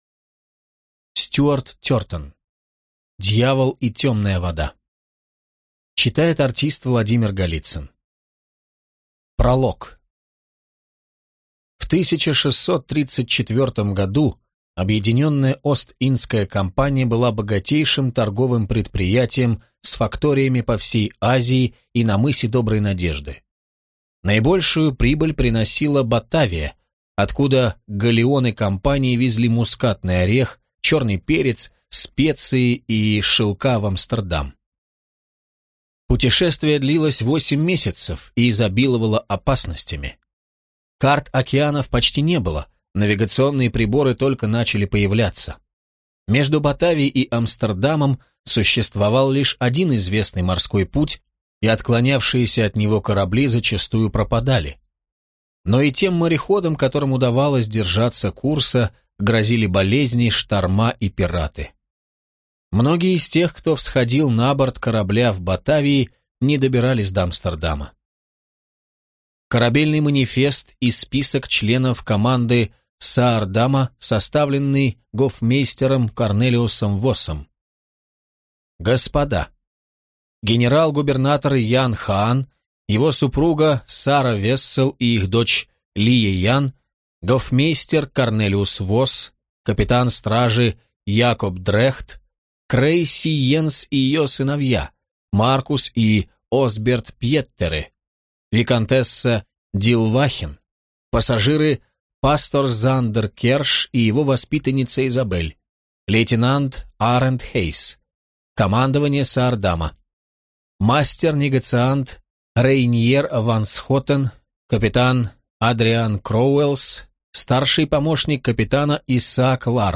Аудиокнига Дьявол и темная вода | Библиотека аудиокниг